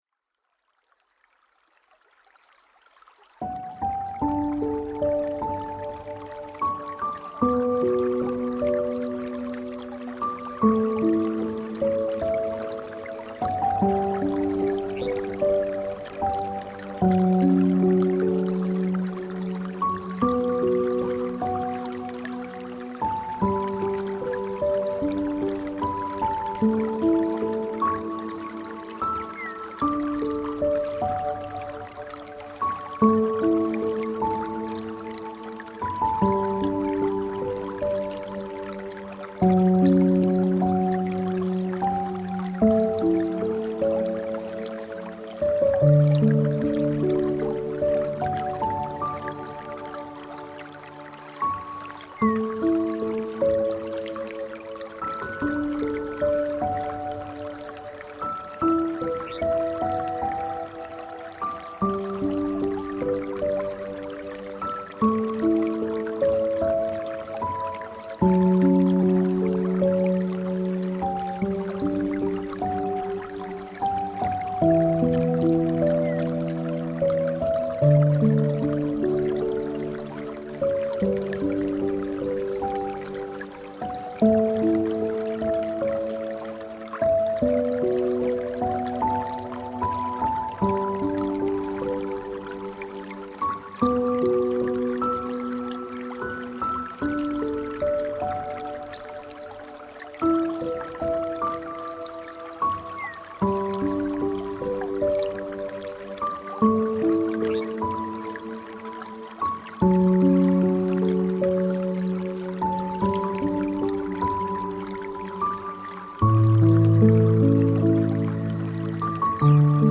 Natur + Piano 100% werbefrei | Stressabbau & Herzheilung